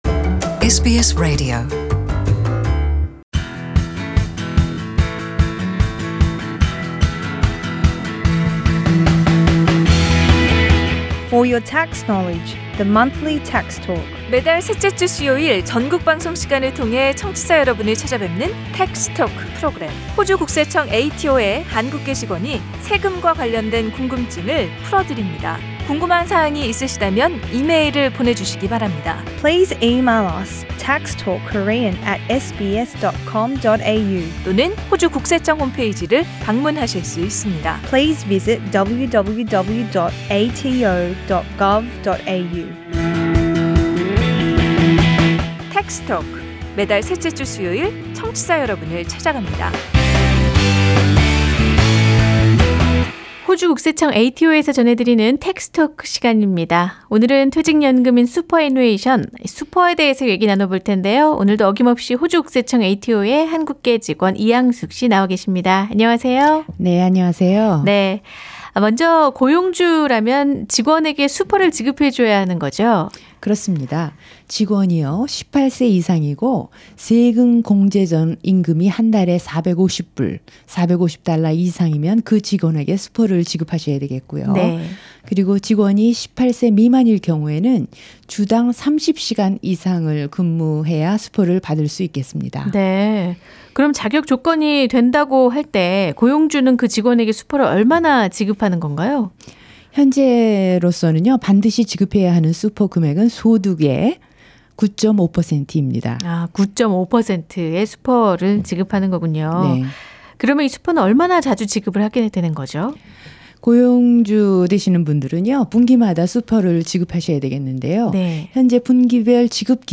Paying super is an important part of being an employer. Here’s an audio feature that explains superannuation obligations for employers.